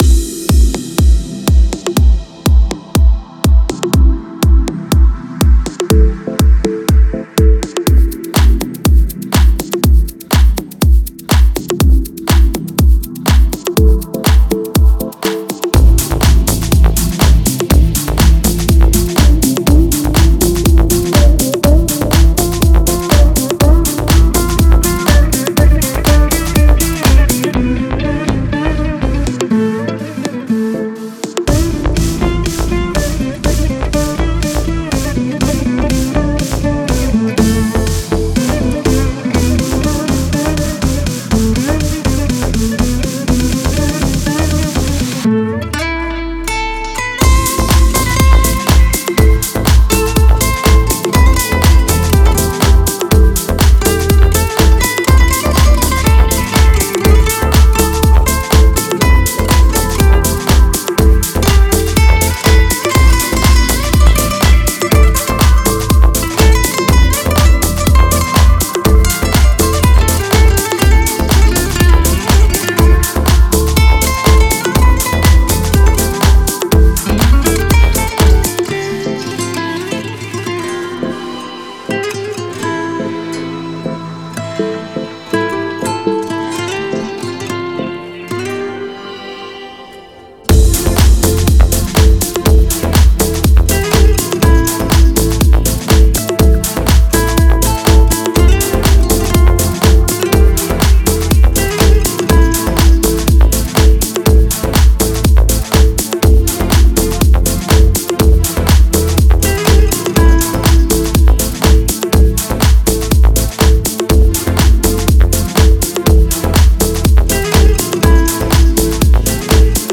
Release Date:10 August 2018 بی کلام